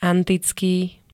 antický [-t-] -ká -ké príd.
Zvukové nahrávky niektorých slov